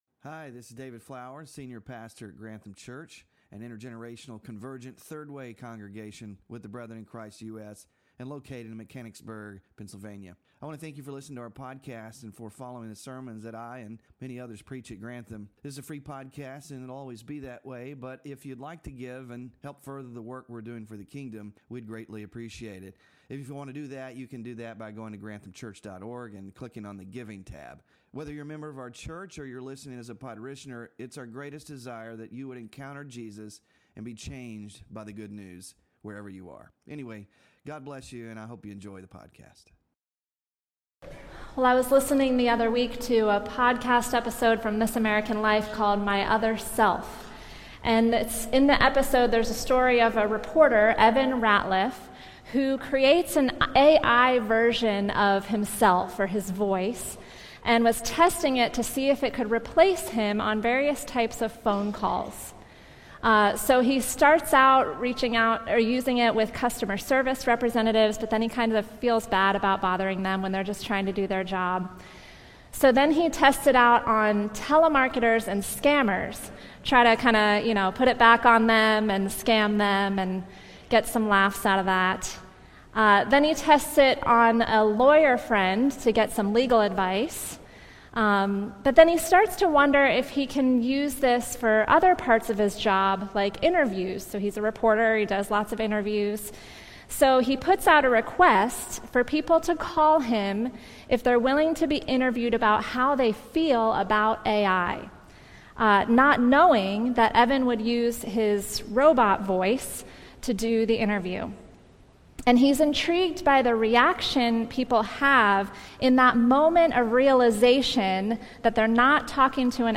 Sermon Focus: We live in a culture of manipulation, spinning the truth, and keeping our options open. But Jesus calls out our tendency to deceive and hold back and invites us into a life of integrity and honesty.